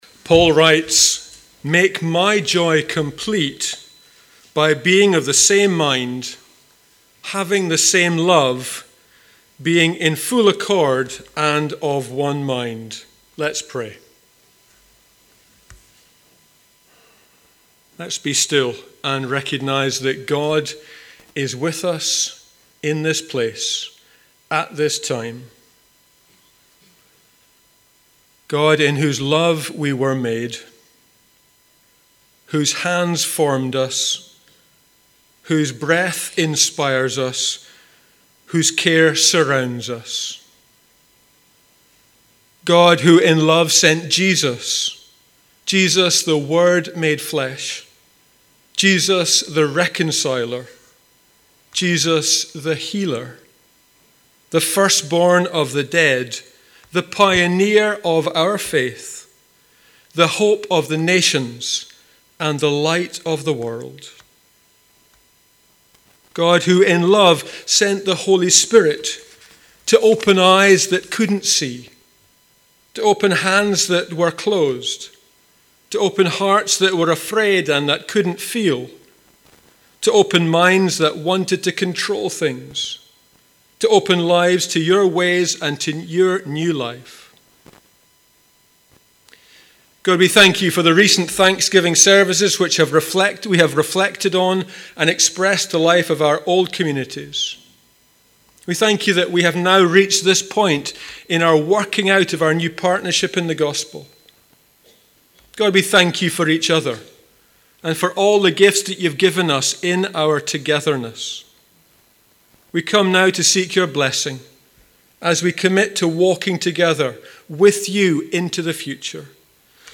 Penicuik Trinity Community Church was led by Presbytery on Thursday 7 April.
Opening Gathering Prayer
prayer1.mp3